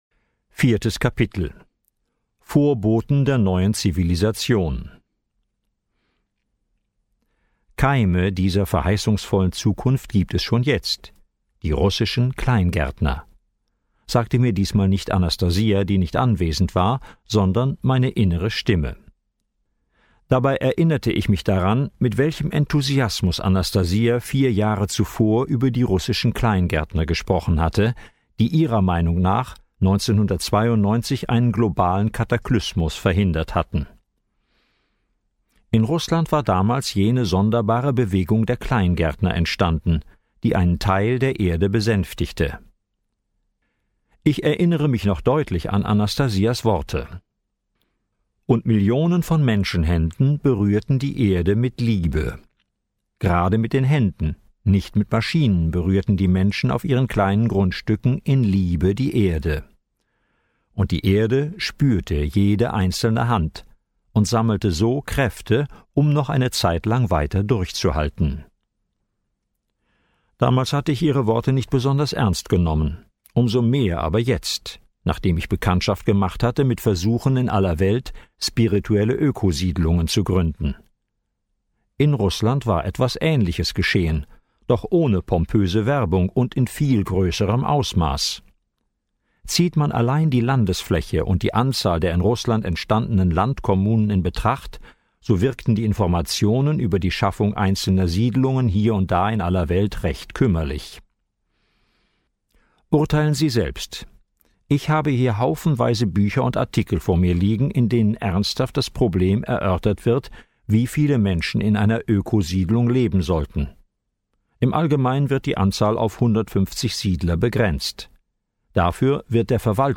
MP3-CD mit der vollständigen Hörbuchfassung